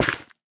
Minecraft Version Minecraft Version 1.21.5 Latest Release | Latest Snapshot 1.21.5 / assets / minecraft / sounds / block / nylium / break2.ogg Compare With Compare With Latest Release | Latest Snapshot
break2.ogg